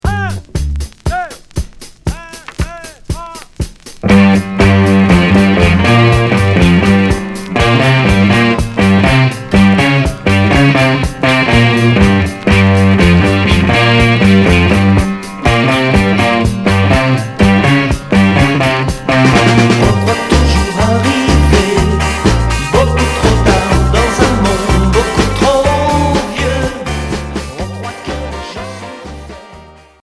Pop progressif